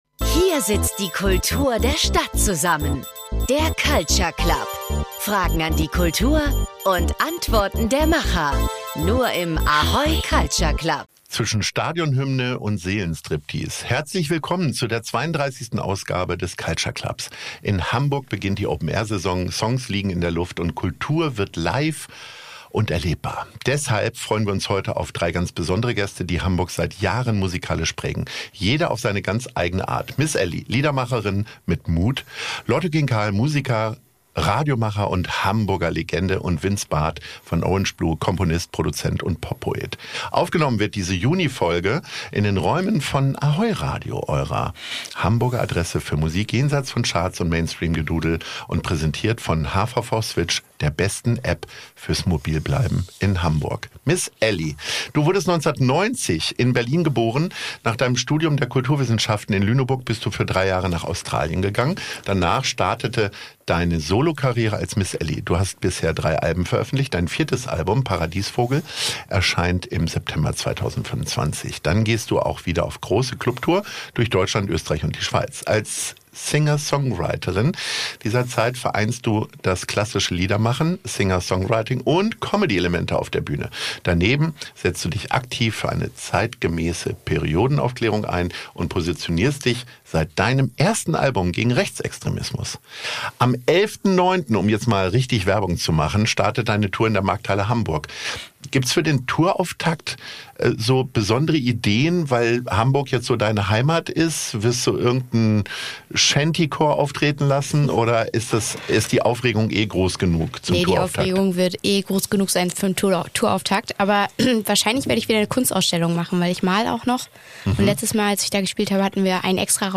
Und wie verändert sich Musikkonsum, wenn man selbst ständig Musik macht? Ein ehrliches, humorvolles und sehr persönliches Gespräch über Kunst, Krach, Kameradschaft – aufgenommen bei ahoy radio und präsentiert von hvv switch, der besten App fürs mobil bleiben in Hamburg.